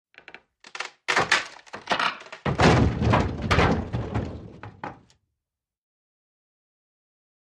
IMPACTS & CRASHES - WOOD WOOD: EXT: Small wooden structure collapse, creak, crack, crash.